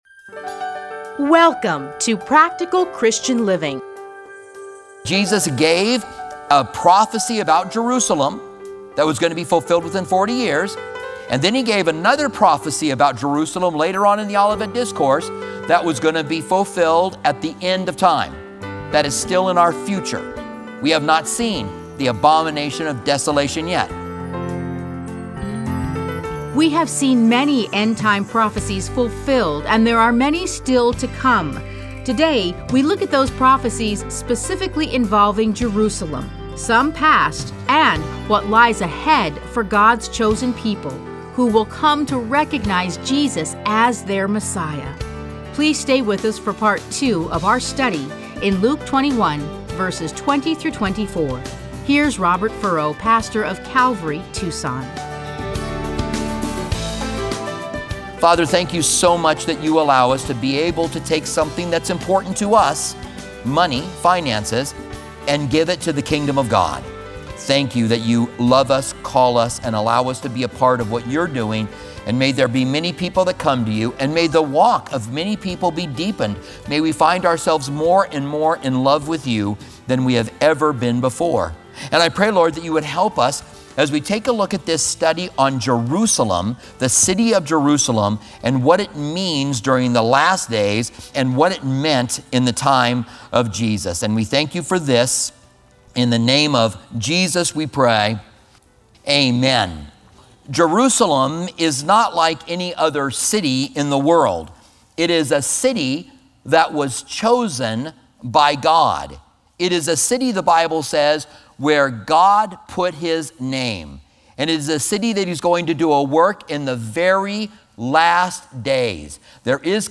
Listen to a teaching from Luke 21:20-24.